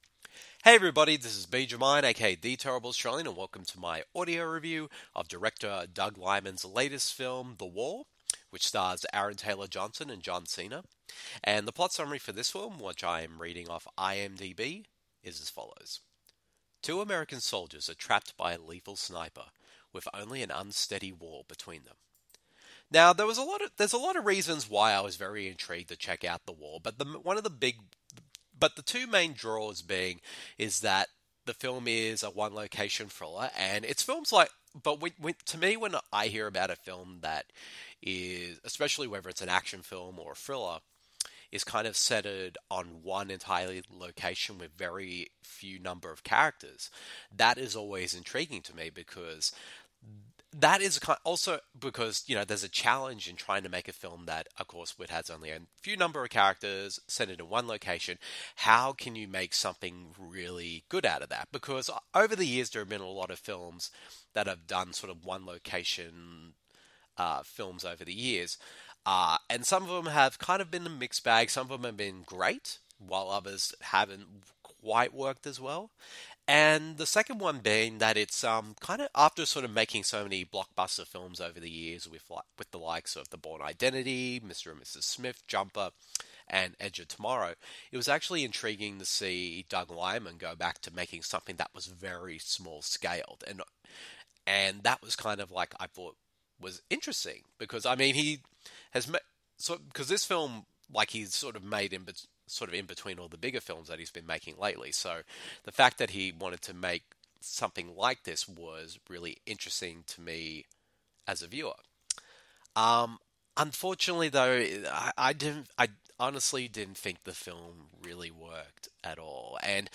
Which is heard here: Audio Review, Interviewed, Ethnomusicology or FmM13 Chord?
Audio Review